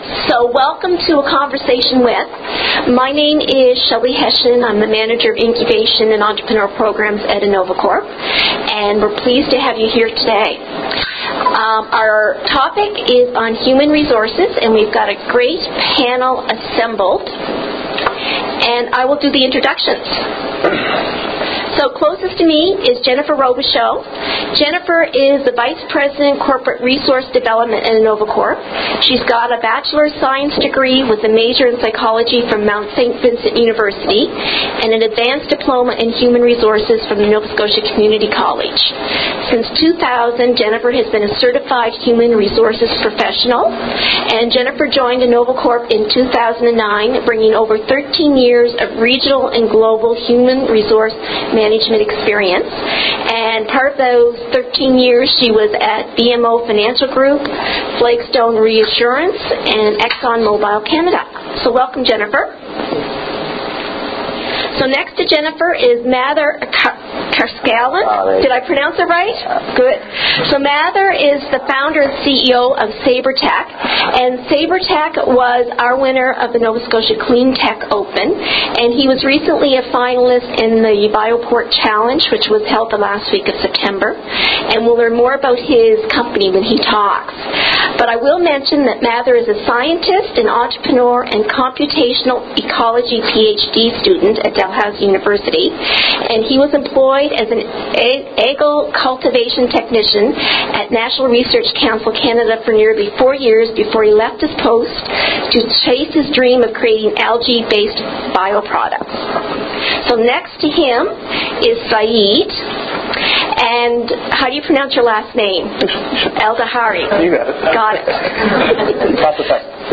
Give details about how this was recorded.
CLICK HERE TO LISTEN TO A PODCAST OF THE EVENT BELOW THAT TOOK PLACE ON OCTOBER 18, 2012, AT THE TECHNOLOGY INNOVATION CENTRE.